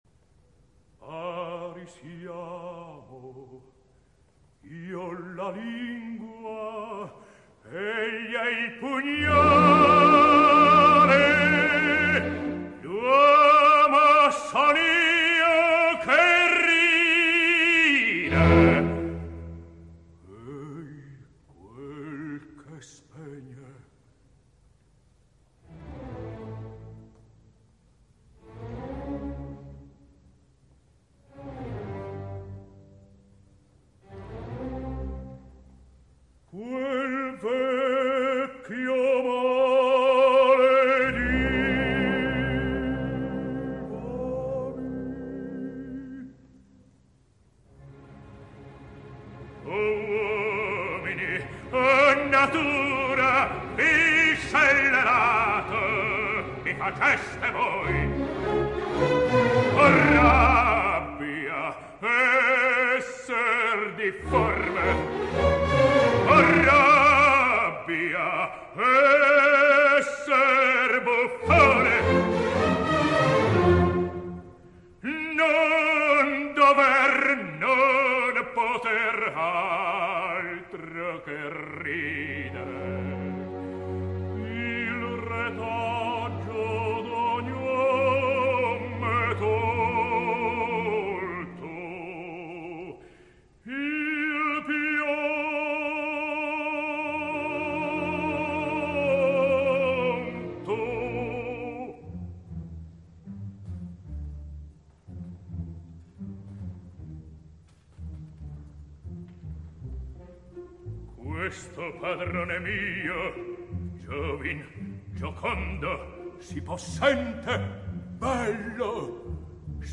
opera completa, registrazione in studio.
basso    • baritono    • tenore